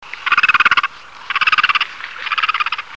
Airone bianco maggiore – Airòn bianco
Egretta alba
Qualche volta una nota gracchiante; più rumoroso nella colonia dove emette una serie di suoni gracchianti.
Airone-bianco-maggiore.mp3